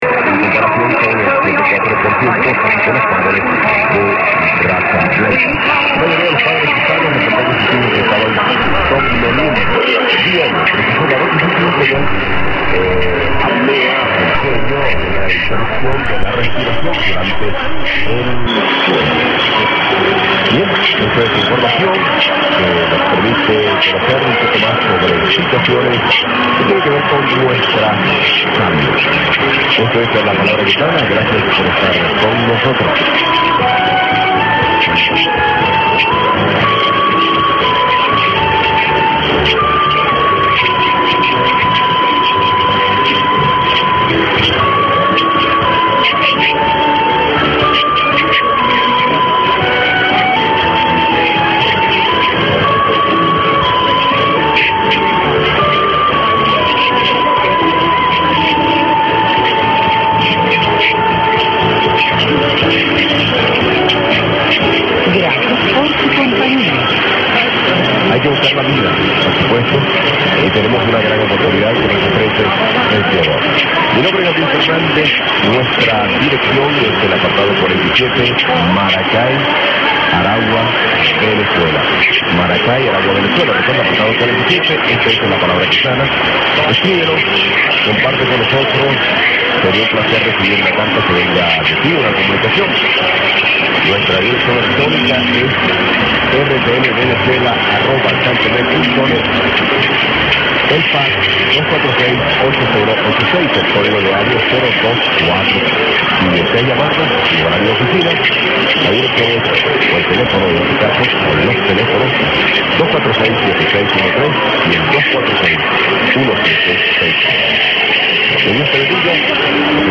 I have included an unid ss from 800. Sounds more like RCN than TWR, can anyone verify that is an RCN that I am hearing in there??